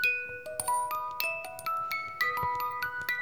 Speeldoosje_1.wav